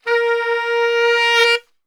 A#2 SAXSWL.wav